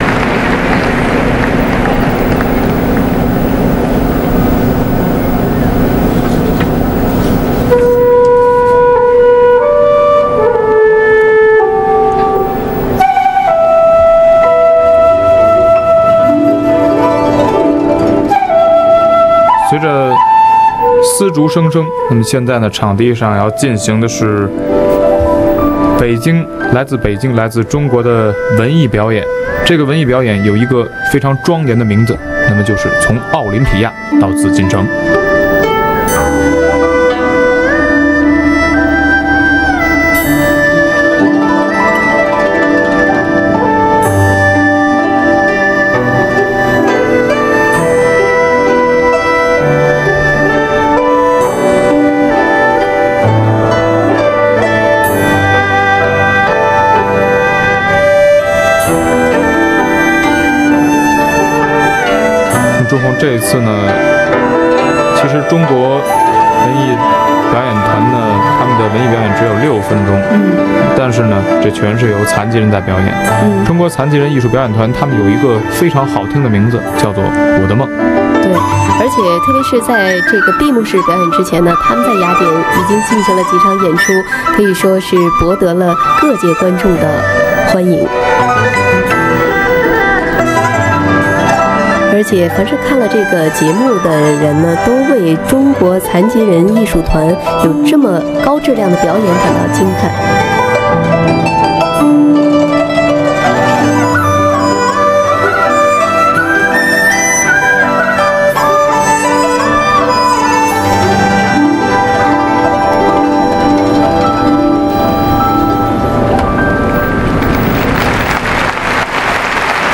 「千手観音」：中国のお正月特別番組での上演（約60MB） 「千手観音」：パラリンピック閉会式での上演（約30MB）